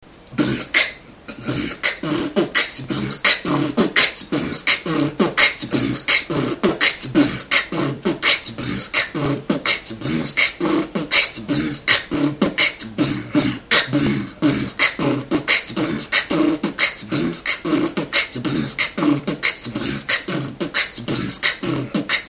Выкладываем видео / аудио с битбоксом
Bm с kch дробь очень бысро b kch с